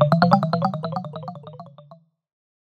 Category: Huawei Ringtones